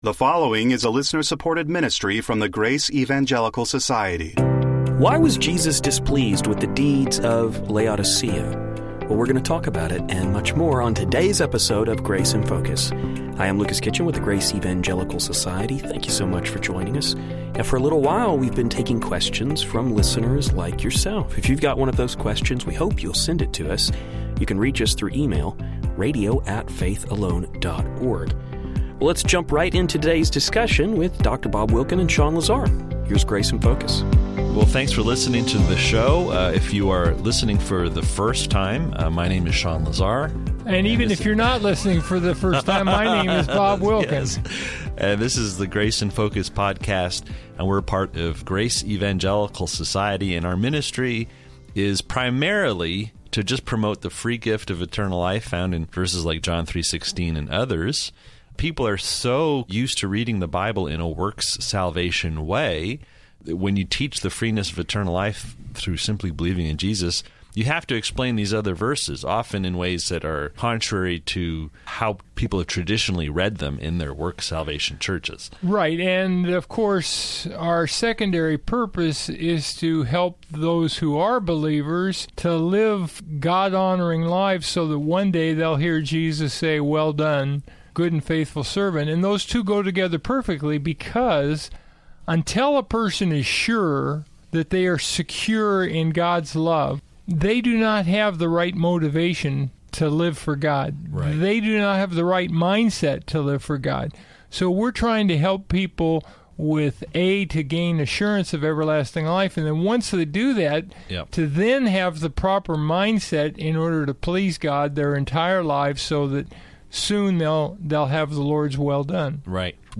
How do we reconcile this passage with eternal security? These and many more issues will be discussed on the show.